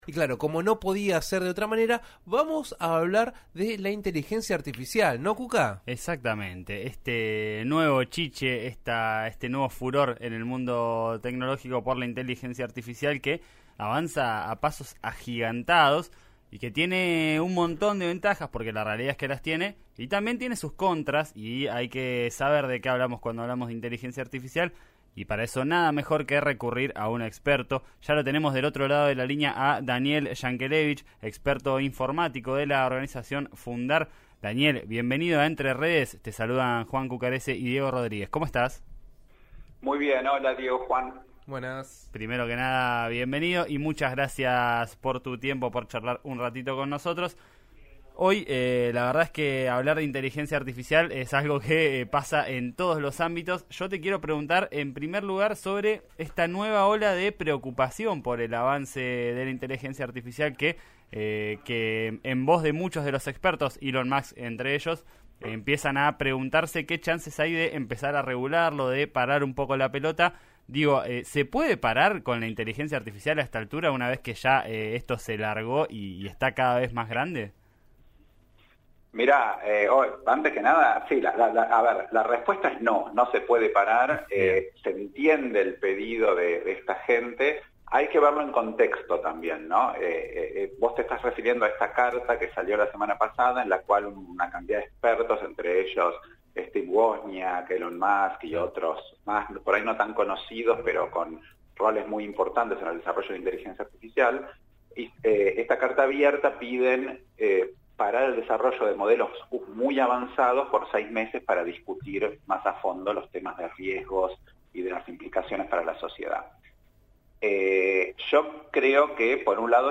El experto dialogó con 'Entre Redes', de RÍO NEGRO RADIO.